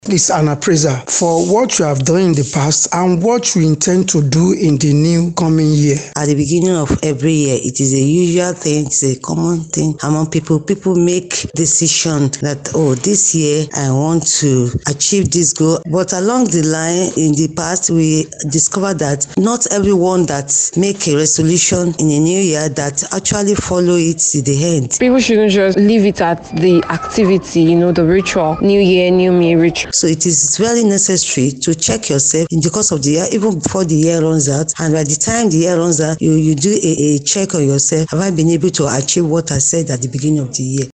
some residents of ibadan the oyo state capital